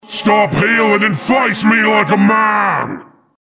This is an audio clip from the game Team Fortress 2 .